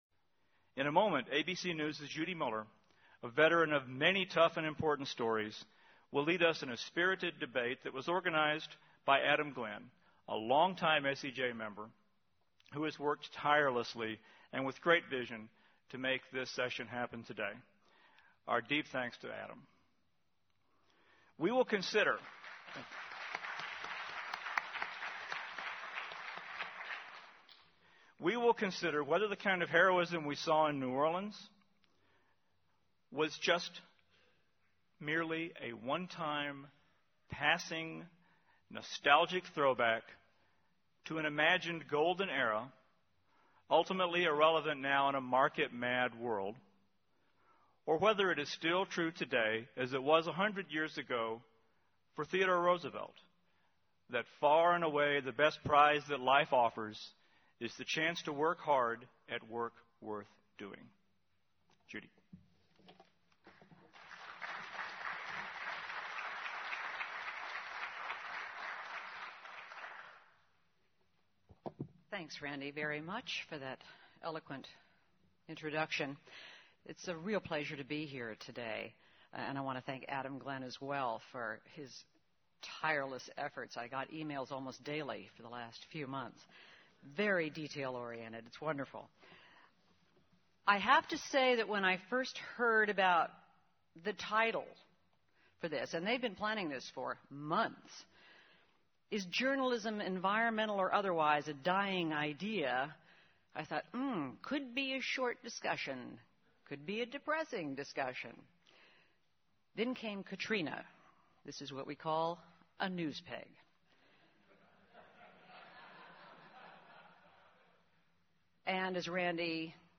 Friday, September 30, Opening Plenary Session with Congressman Pombo (MP3/16.6MB) Saturday, October 1, Concurrent Sessions 4, THE LAW: What's in a Name?